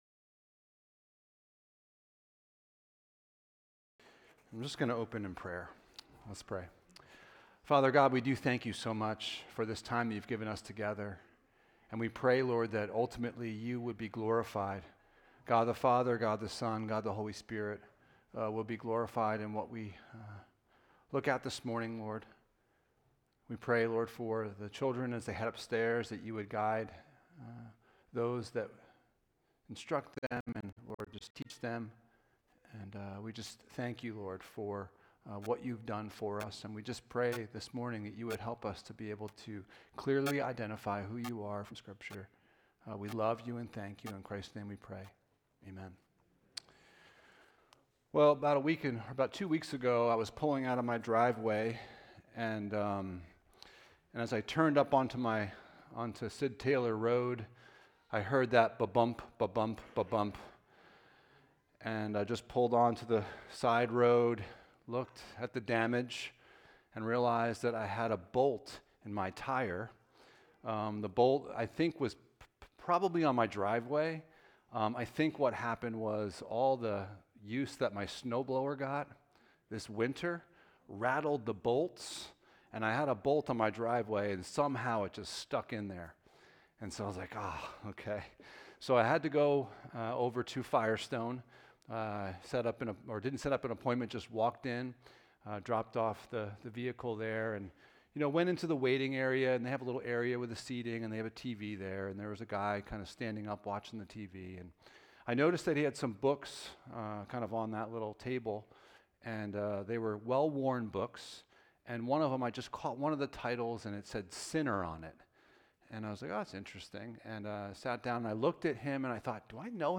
The Supremacy of Christ Passage: Colossians 1:15-20 Service Type: Sunday Morning « Has The Gospel Changed You?